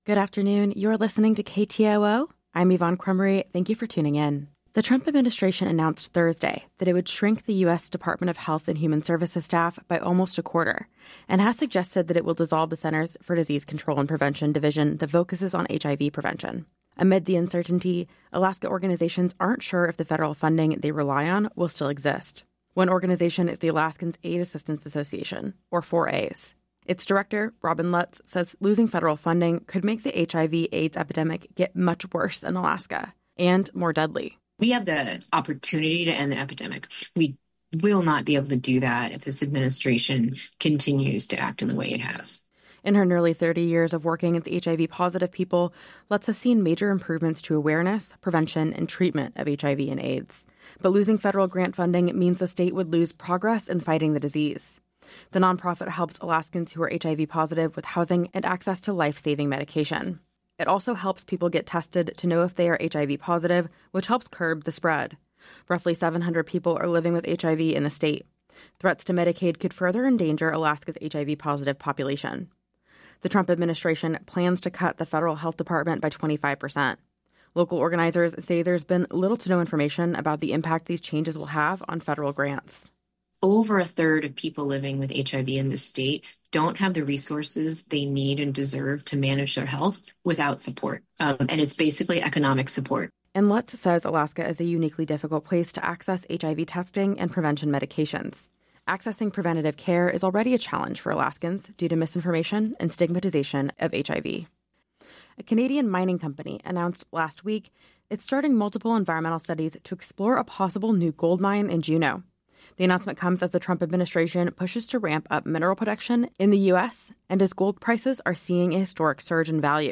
Newscast – Friday, March 28, 2025 - Areyoupop